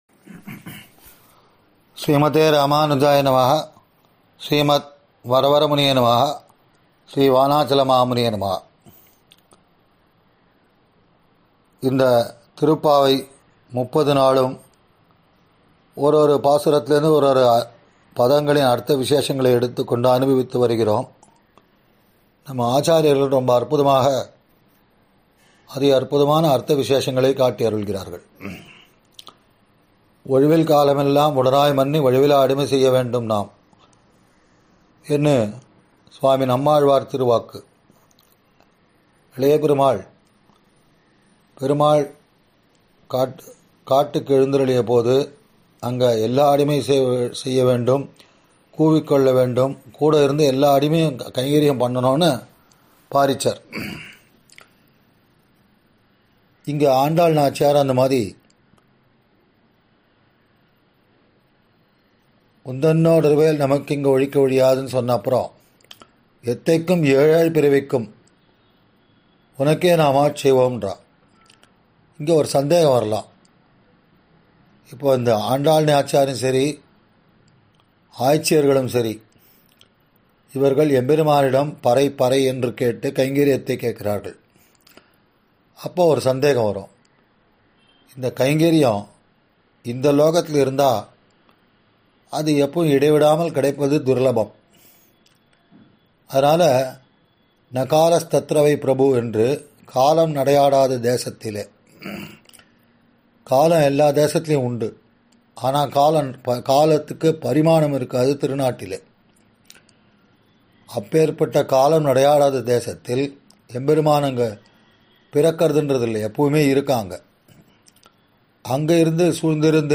சார்வரி ௵ மார்கழி ௴ மஹோத்ஸவ உபன்யாசம் –